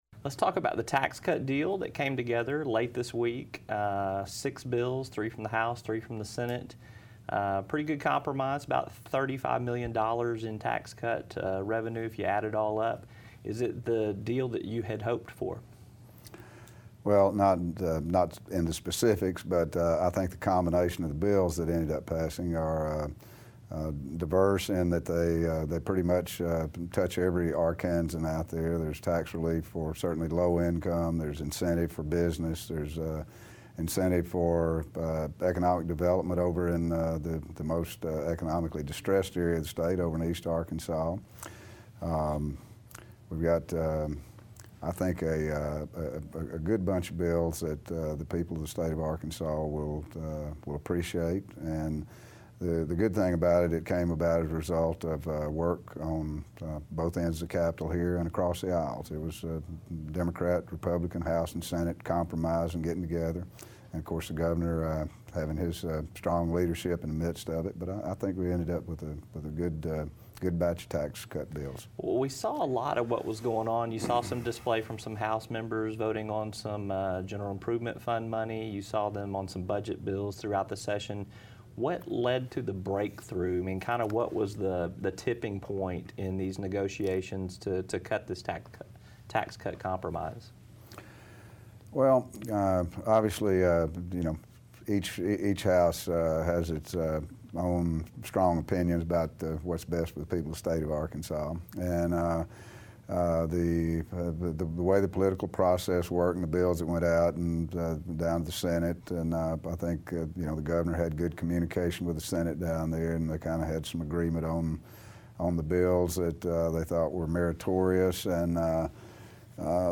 House Speaker Robert Moore discusses tax-cuts, and paying for state highways and roads